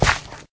gravel1.ogg